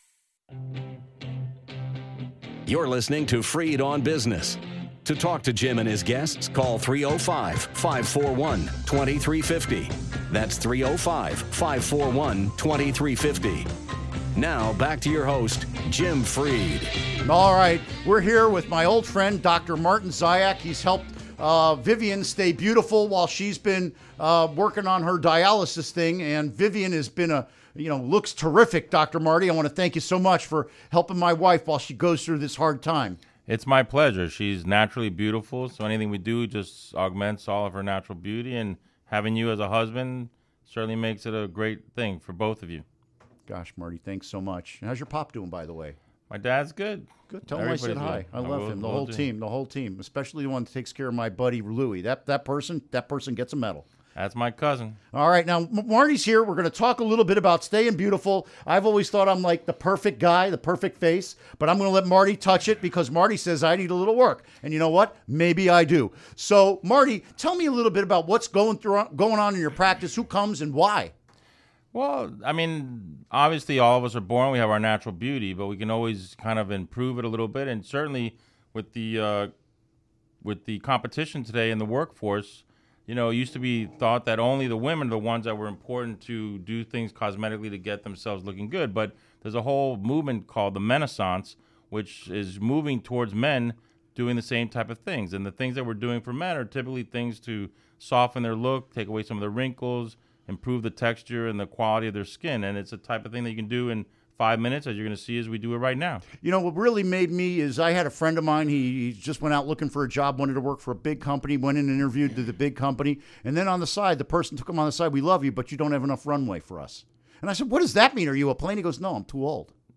He will discuss how to look good for the holidays, the Miami Peel, Botox and the importance of rectal exams. Interview Segment Episode 397: 12-08-16 Download Now!